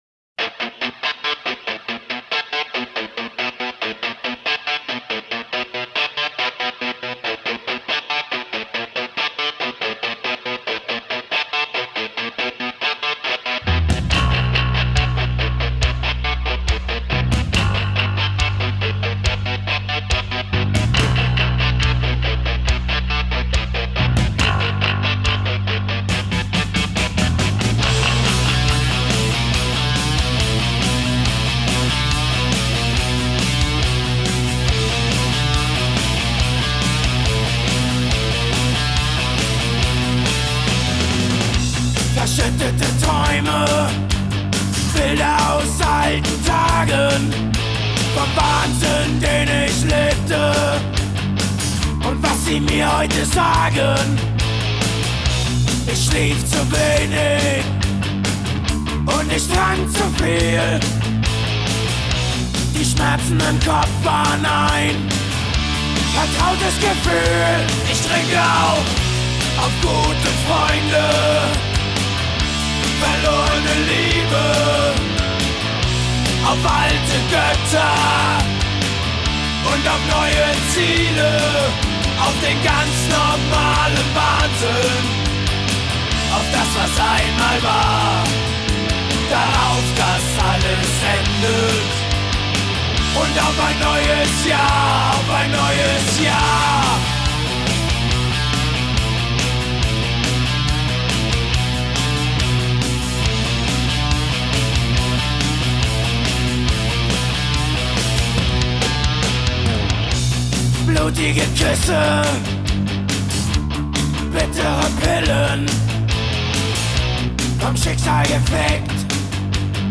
Rock GER